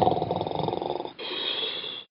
Бен спит и похрапывает